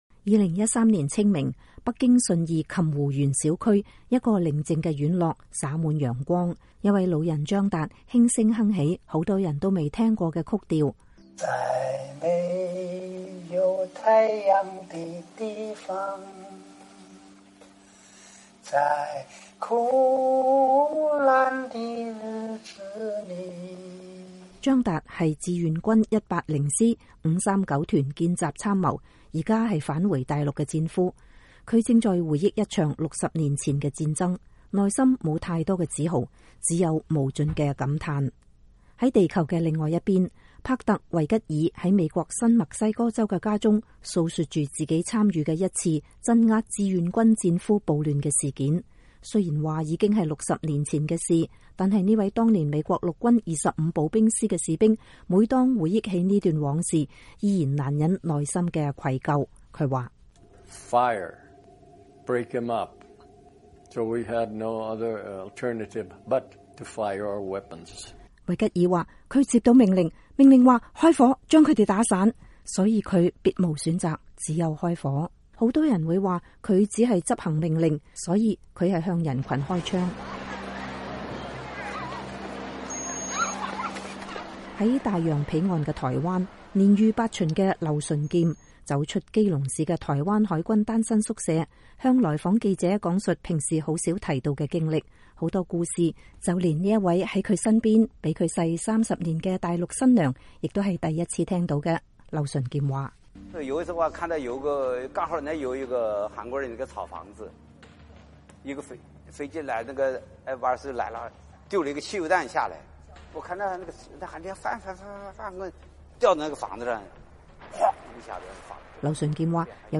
65年後的今天，美國之音《解密時刻》特別推出 《志願軍戰俘》電視系列紀錄片，以塵封的史料、戰場拍攝的鏡頭和太平洋兩岸進行的採訪，為您再現當年戰爭的殘酷，揭示“抗美援朝”、“保家衛國”等亮麗光環背後的真實，再現兩萬多中國戰俘這個被遺忘的特殊群體在那場被遺忘戰爭中的無奈與坎坷的人生。